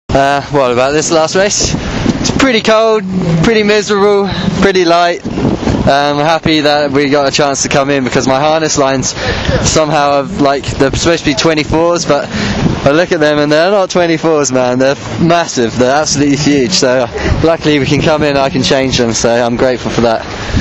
More interviews… – Formula Windsurfing